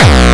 VEC3 Bassdrums Dirty 17.wav